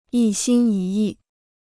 一心一意 (yī xīn yī yì) Free Chinese Dictionary